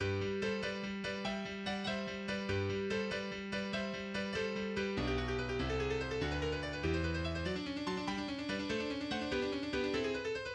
Genre Sonate pour piano
• Allegro, en sol majeur, à